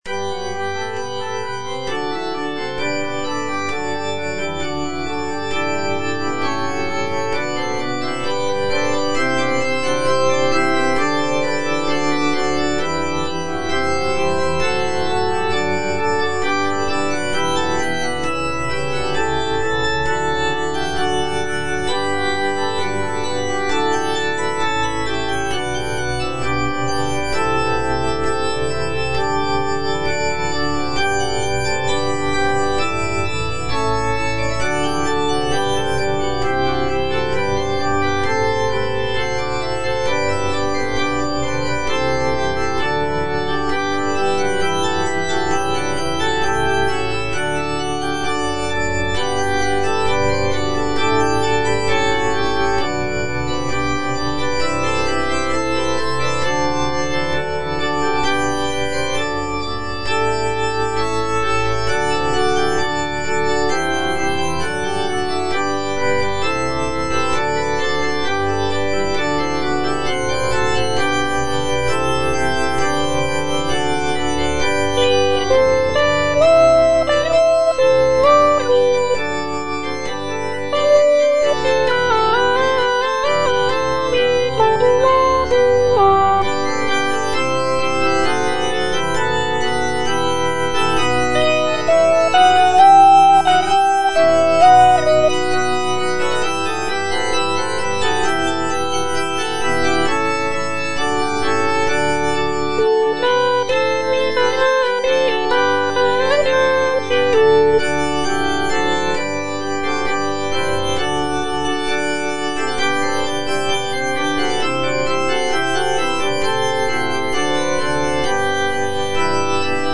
M.R. DE LALANDE - CONFITEBOR TIBI DOMINE Memor erit in saeculum (petit choeur - soprano I) (Voice with metronome) Ads stop: auto-stop
It is a setting of the Latin text from Psalm 111, expressing gratitude and praise to the Lord. Lalande's composition features intricate polyphony, lush harmonies, and expressive melodies, reflecting the Baroque style of the period.